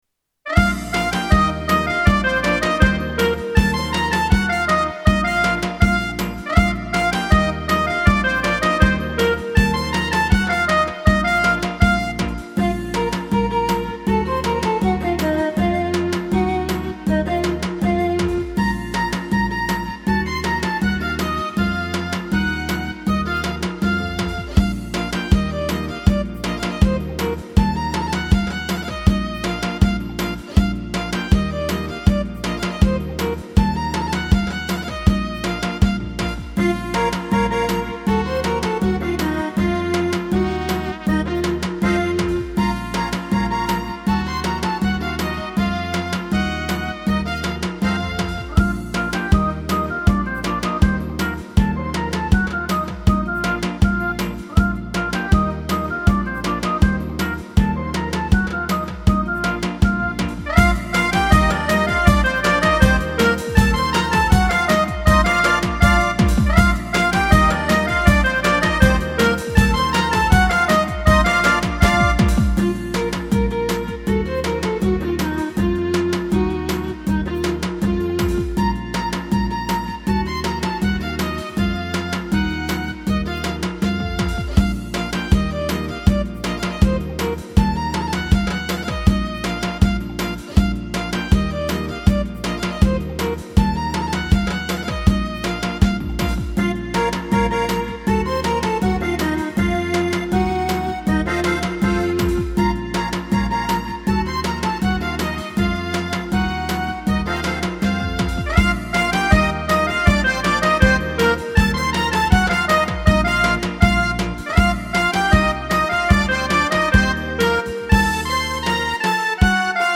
обработка народной мелодии